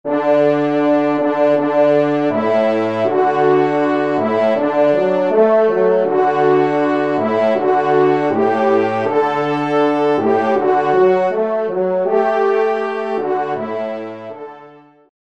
Genre : Fantaisie Liturgique pour quatre trompes
Pupitre de Basse